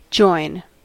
Ääntäminen
IPA : /ˈdʒɔɪn/